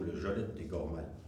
Catégorie Locution ( parler, expression, langue,... )